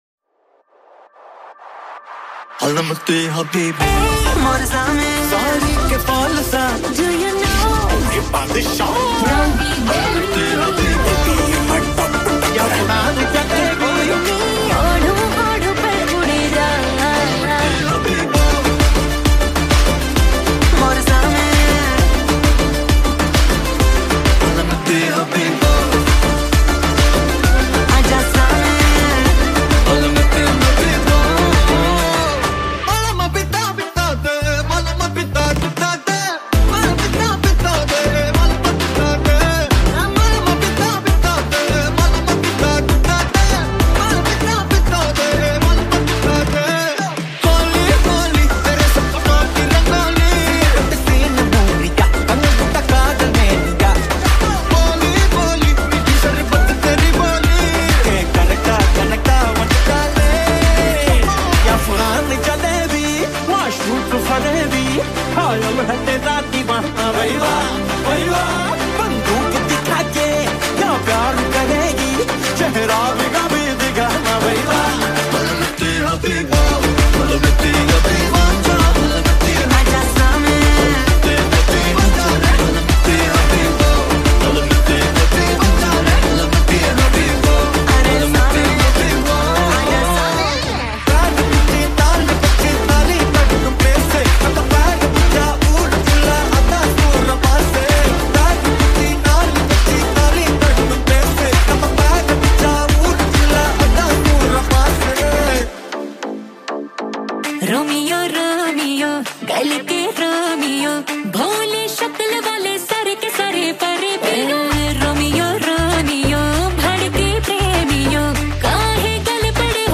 High quality Sri Lankan remix MP3 (3.5).